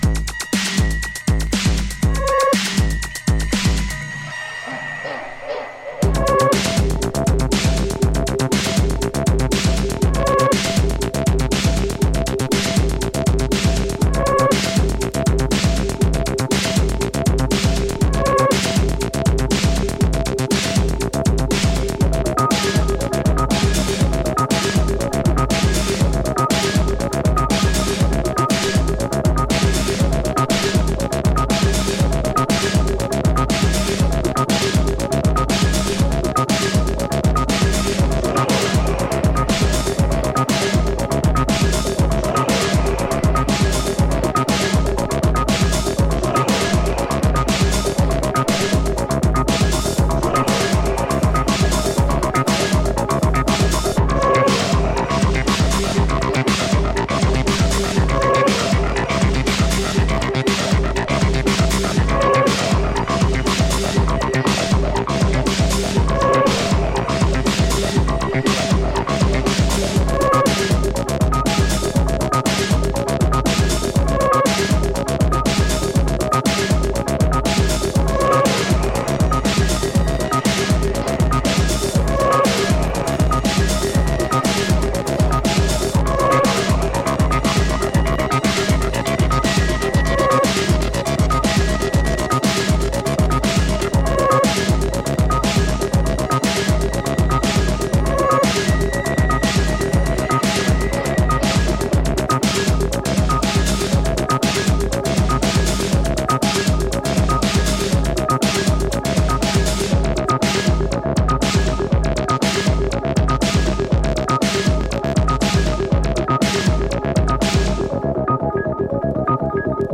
EBM/Industrial
Contundent elektro is the appropriate adjective for this cut